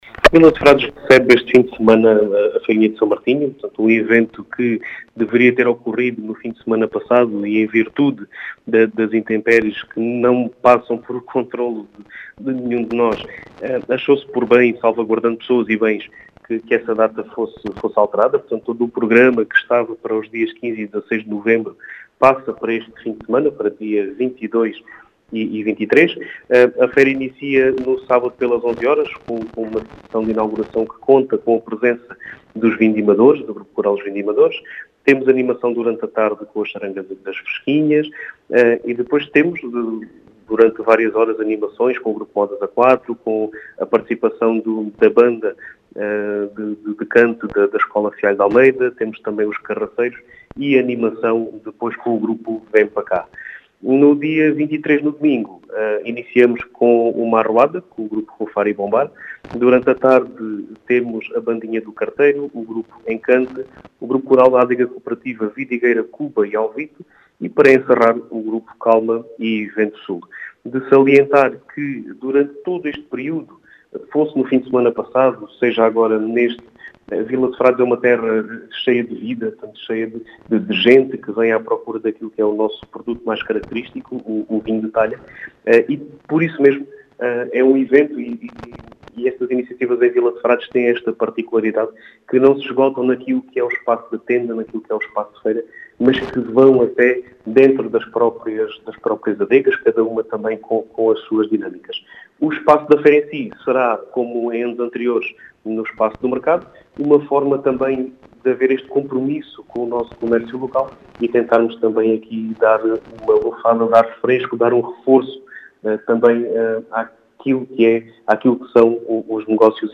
As explicações são do presidente da junta de freguesia de Vila de Frades, Diogo Conqueiro, que realça a importância deste evento, que “entra” dentro das adegas.